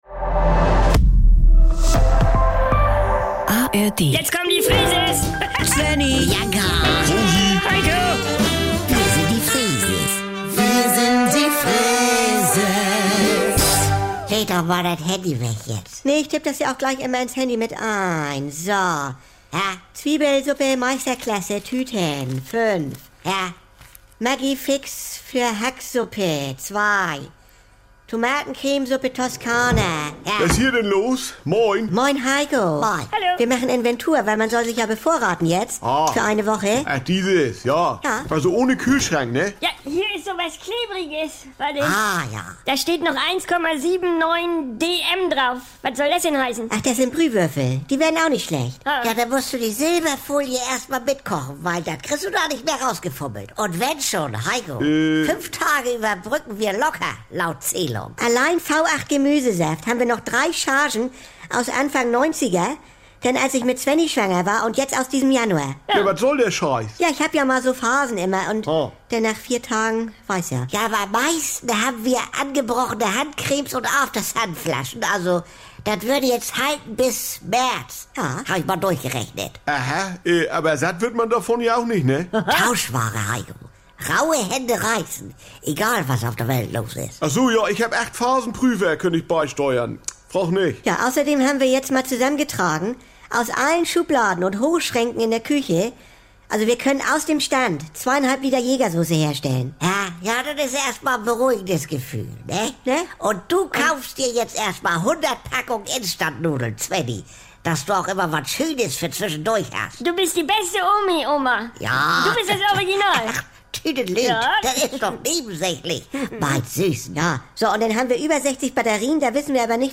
Hier gibt's täglich die aktuelle Freeses-Folge, direkt aus dem Mehrgenerationen-Haushalt der Familie Freese mit der lasziv-zupackenden Oma Rosi, Helikopter-Mama Bianca, dem inselbegabten Svenni sowie Untermieter und Labertasche Heiko. Alltagsbewältigung rustikal-norddeutsch...
… continue reading 1889 episoade # Saubere Komödien # NDR 2 # Komödie # Unterhaltung